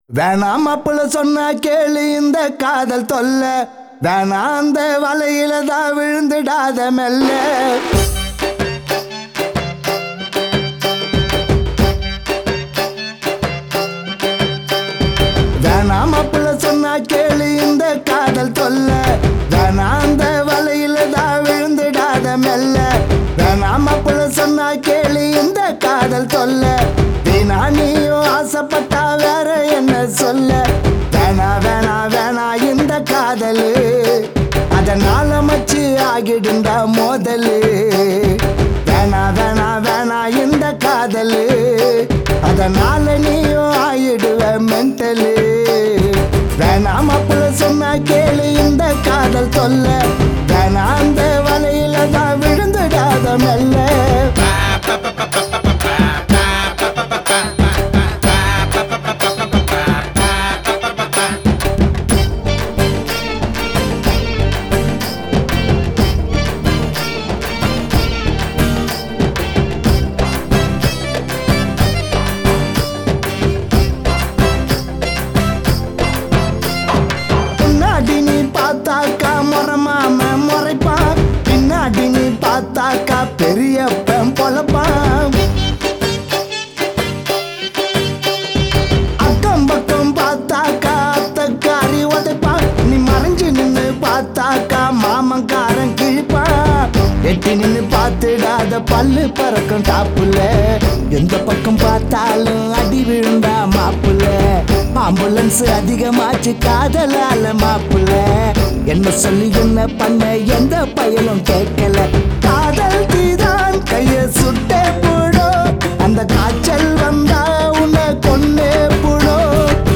Category: TAMIL DJ REMIX SONGS